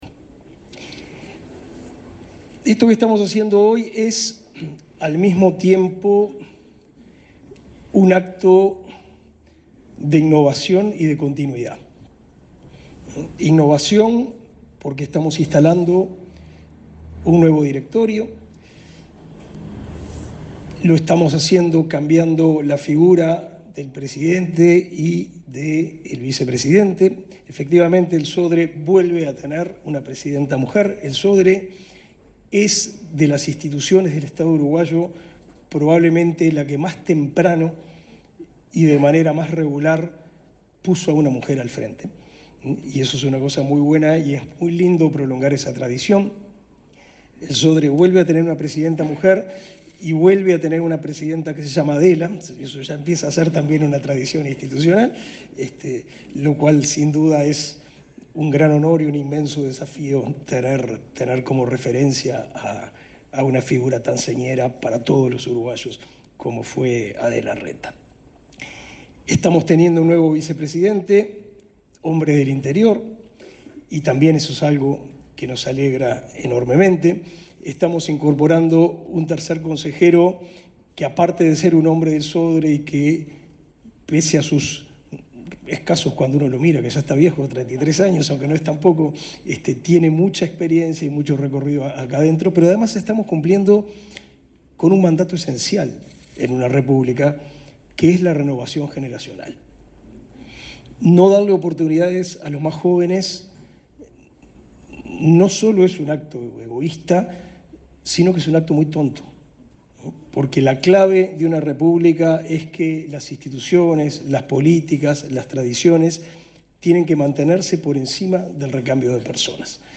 Palabras del ministro de Educación y Cultura, Pablo da Silveira
Este miércoles 5, el ministro de Educación y Cultura, Pablo da Silveira, participó del acto de toma de posesión del cargo del nuevo directorio del